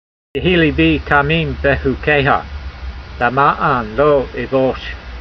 v80_voice.mp3